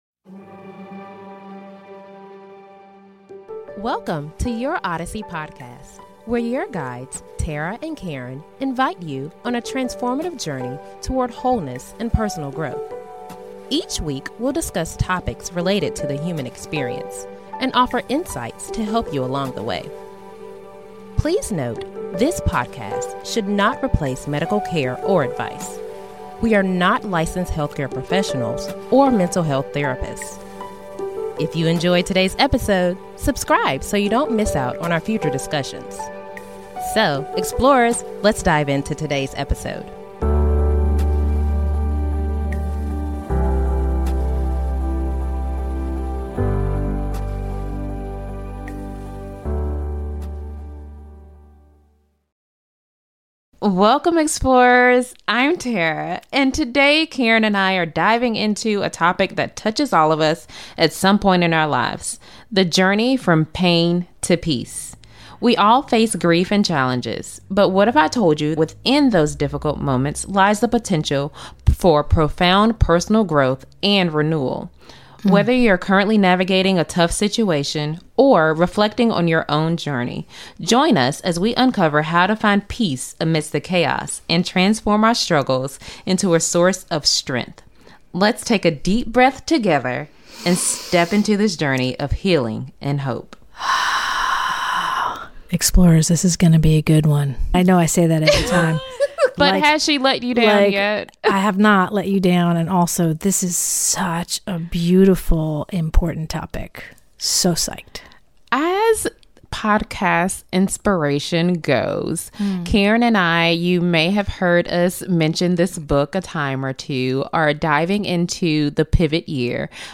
They discuss the universal experiences of suffering and hardship, explaining how these moments can be sources of personal growth and renewal. With insights from various philosophical, psychological, and theological perspectives, they explore strategies such as seeking support, engaging in creative activities, and reframing our experiences to navigate emotional and psychological challenges. Tune in for an inspiring conversation on transforming struggles into a powerful source of joy and strength.